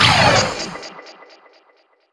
use_speed.wav